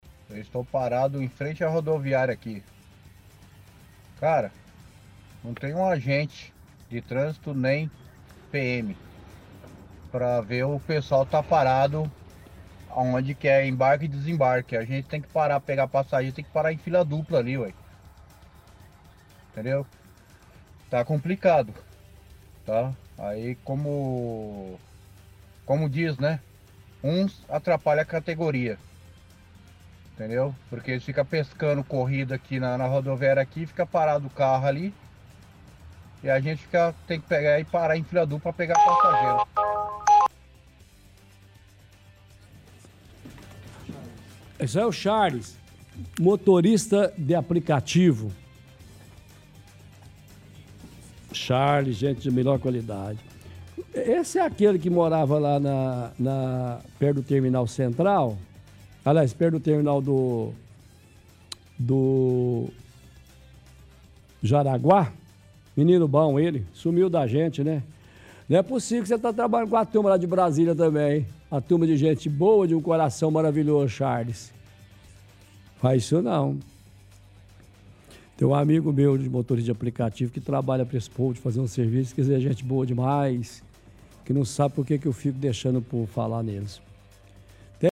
– Ouvinte motorista de aplicativo fala que está na porta da Rodoviária e reclama que não há agentes de trânsito ou policiais na porta para regularem quem está parado de forma irregular.